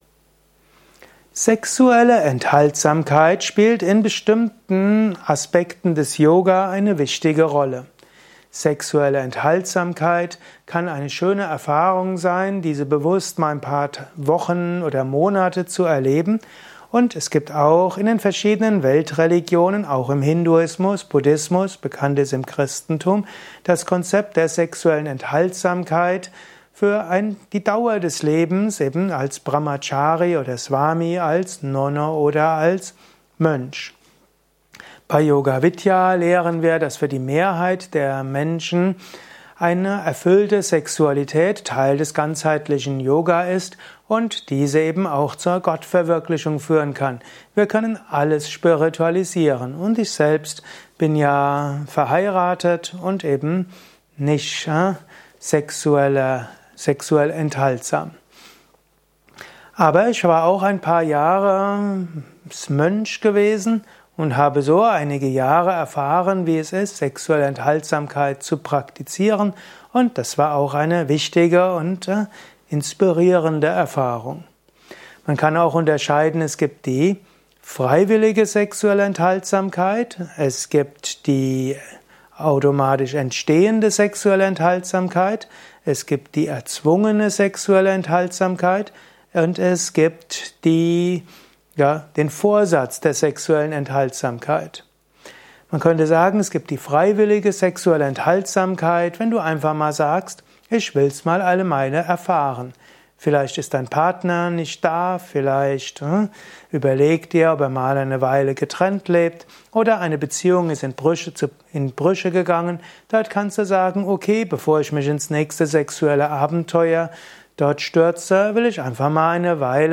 Dieser Vortrag ist Teil des Liebe Podcasts